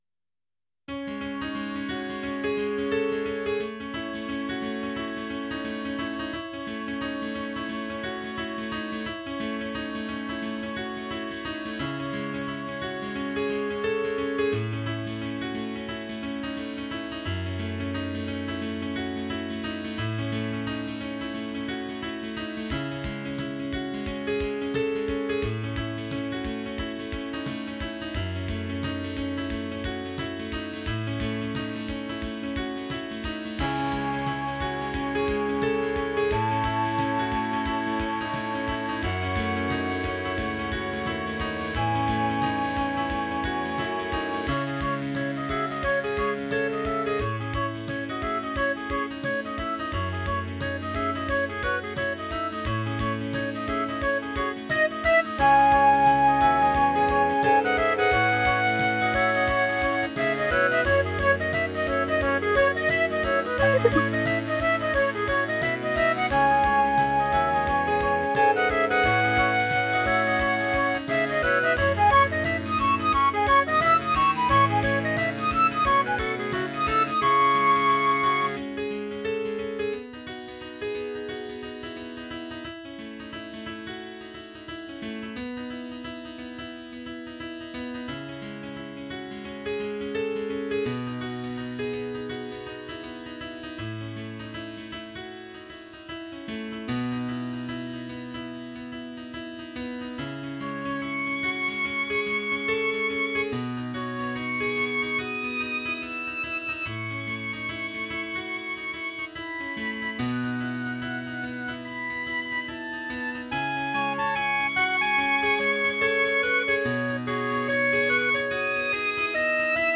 Tags: Piano, Clarinet, Woodwinds
Title The Mountain Is Out (Flute) Opus # 106 Year 2003 Duration 00:05:34 Self-Rating 4 Description A British flautist asked me to arrange this piece for solo flute, trimmed from 6 to 3 minutes.